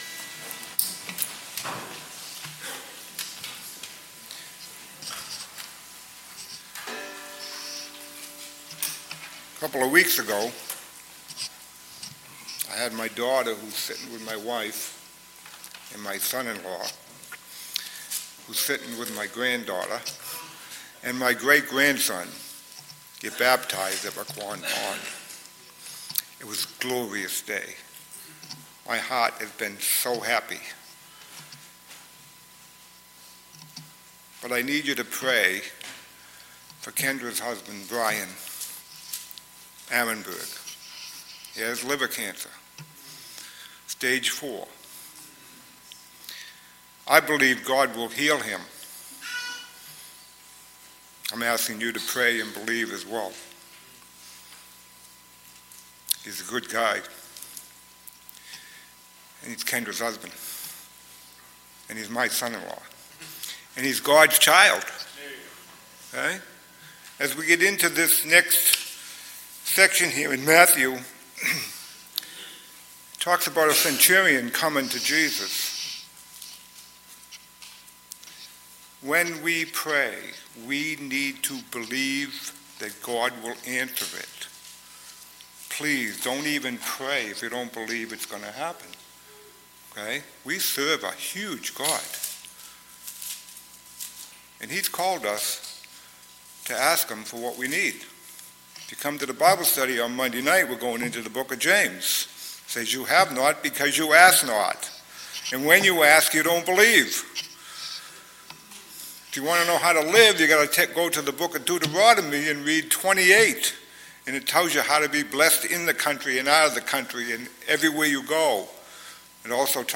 Scripture-Reading-and-Sermon-Sept-82024.mp3